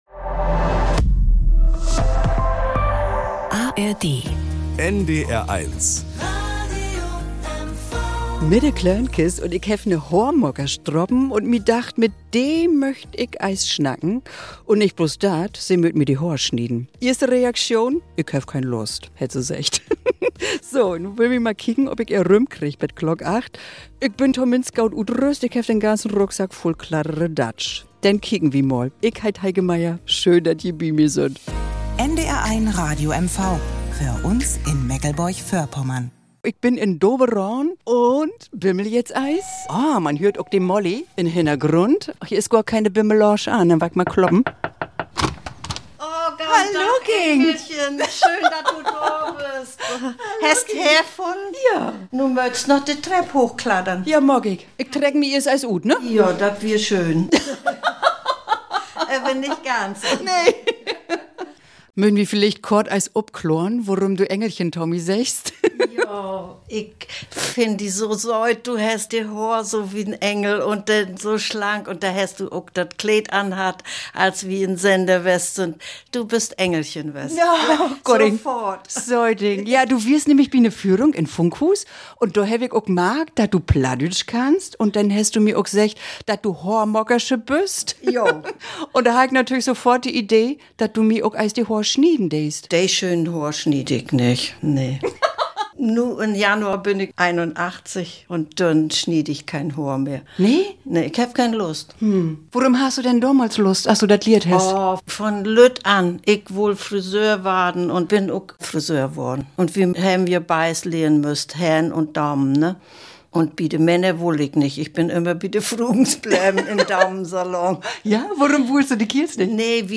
De Klönkist mit Geschichten aus dem Friseursalon ~ Plattdeutsches aus MV Podcast